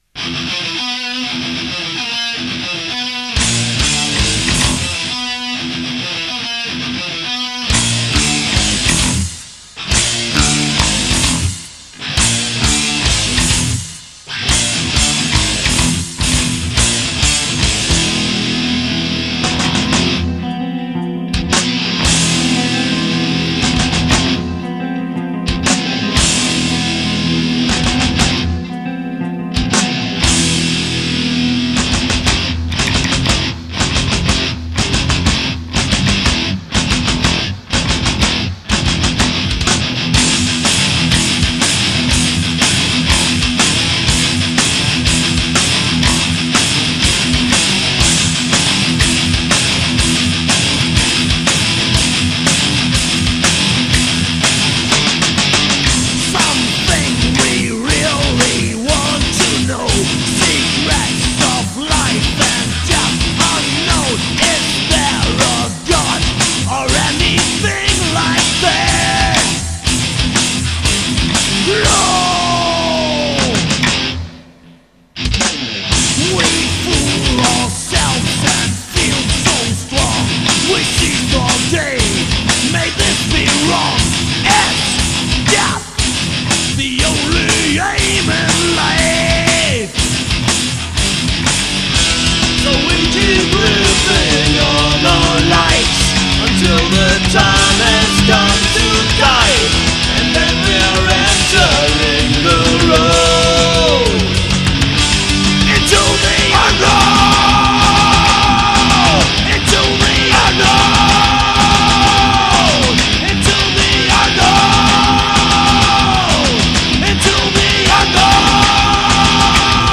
8-Spur/Studio